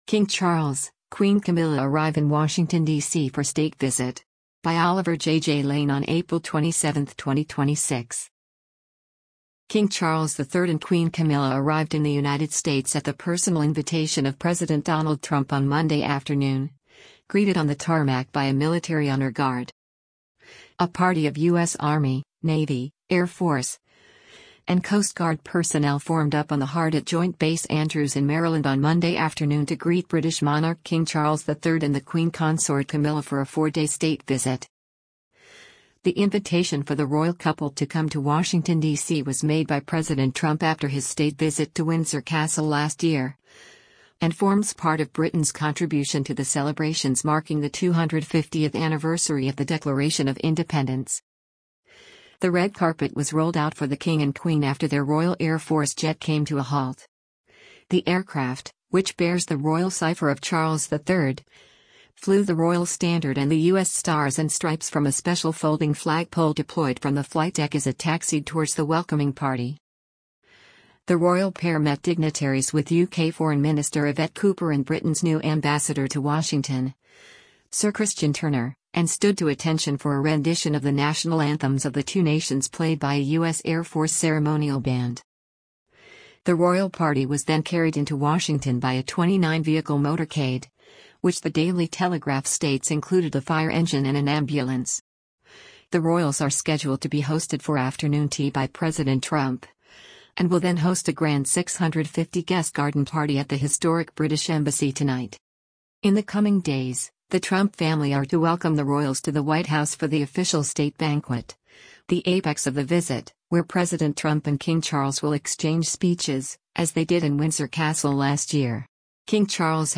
The Royal pair met dignitaries with UK Foreign Minister Yvette Cooper and Britain’s new ambassador to Washington, Sir Christian Turner, and stood to attention for a rendition of the national anthems of the two nations played by a U.S. Air Force ceremonial band.